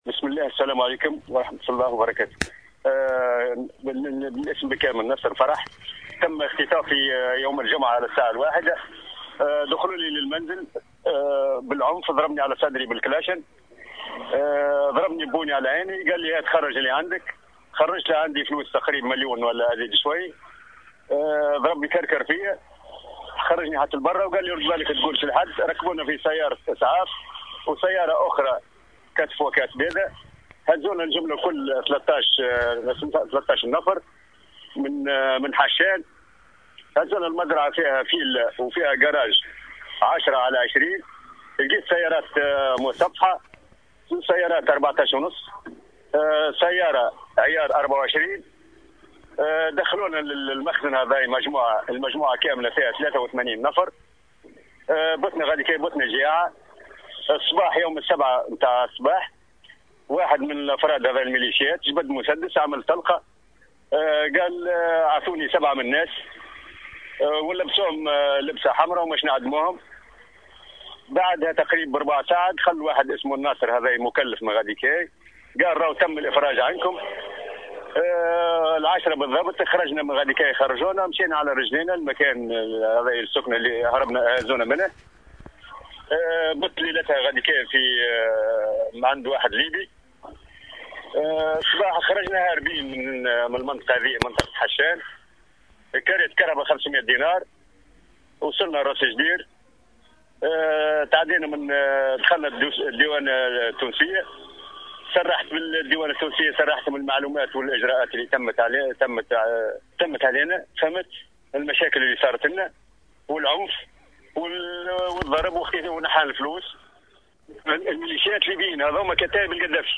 أحد التونسيين المفرج عنهم يتحدث عن تفاصيل اختطافه في ليبيا وهويات الخاطفين